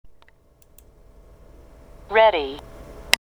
カタカナになると区別がつきませんが、英語では全く発音が違います。
ready（リスニング用音声）